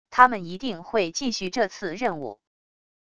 他们一定会继续这次任务wav音频生成系统WAV Audio Player